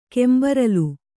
♪ kembaralu